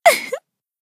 BA_V_Wakamo_Swimsuit_Battle_Shout_2.ogg